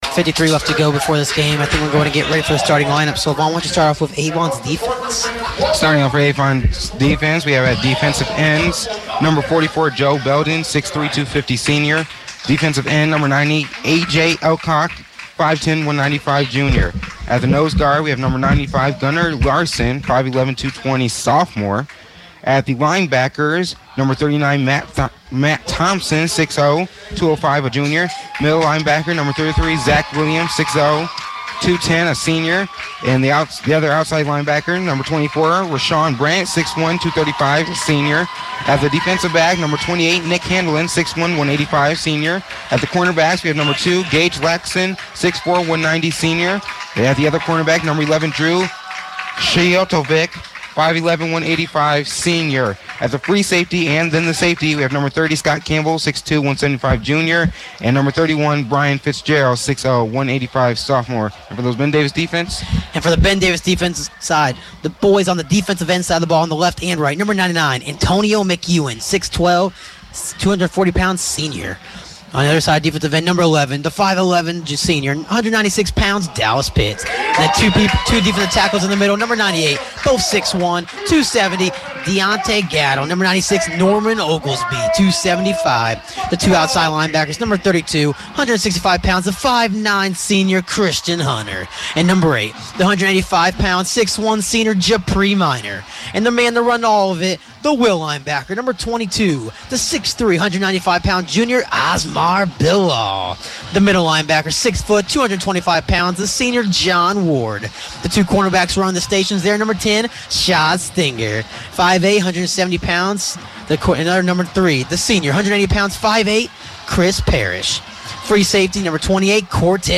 The first quarter of action as the Giants battle the Avon Orioles on August 29, 2014. WBDG's broadcast of the game.